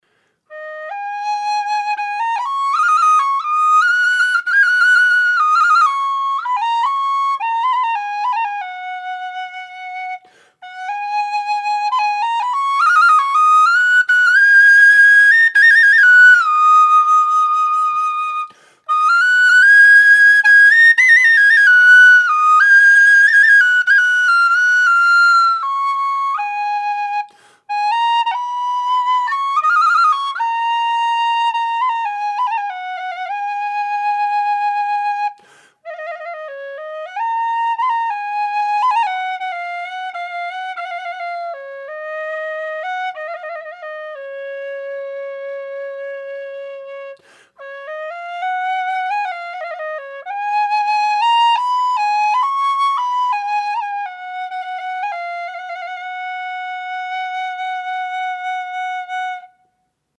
Db/C# Whistles
Db/C# whistle - 110 GBP
made out of thin-walled aluminium tubing with 13mm bore
Audio:Db-impro1.mp3:) (improvisation)